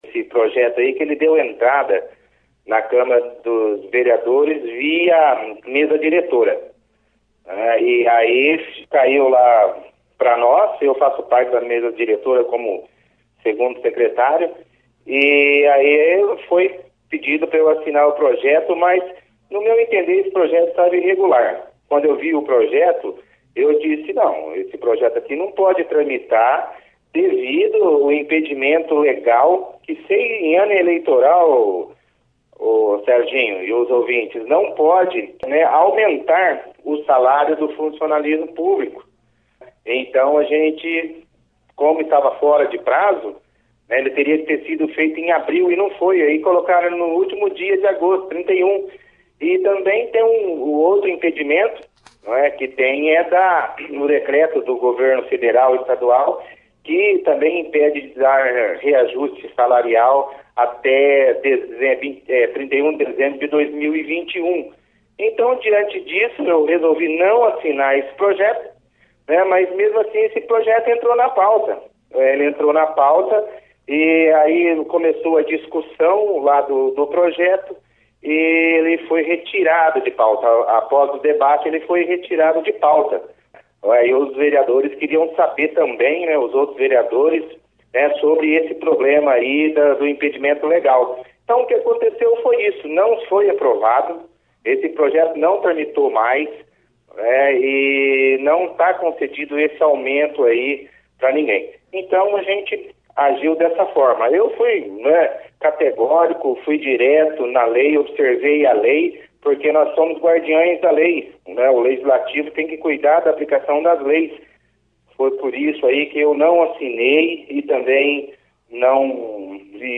Vereador de Itambaracá fala da polemica do aumento de salário de prefeito para mais de 17 mil para o próximo mandato
O vereador Claudemir Pellegrine, (foto), da cidade de Itambaracá , participou da 1ª edição do jornal Operação Cidade desta sexta-feira, 04/09, explicando sobre um projeto de lei via mesa diretora no último dia 31 de agosto de um aumento salarial para prefeito e vice prefeito para próxima legislatura, onde o salário de prefeito passaria de R$10.500,00 para R$17.500,00 e o do vice para R$ 8.750,00.